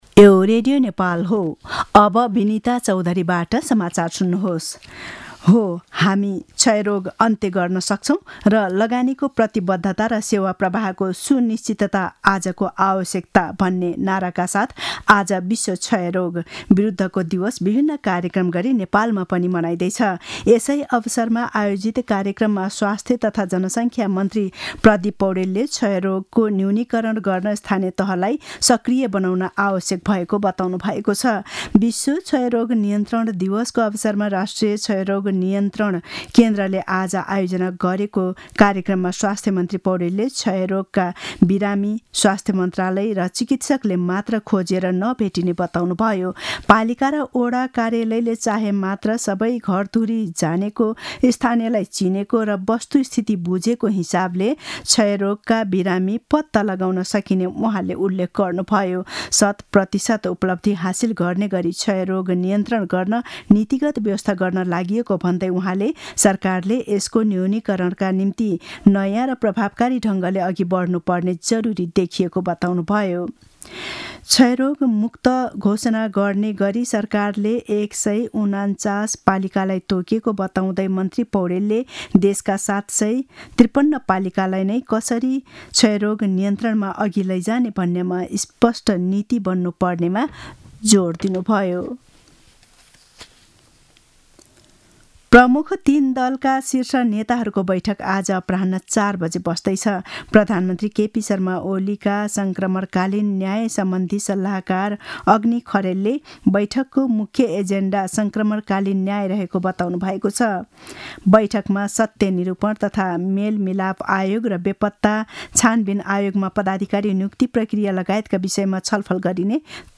दिउँसो १ बजेको नेपाली समाचार : ११ चैत , २०८१
1-pm-news-1-8.mp3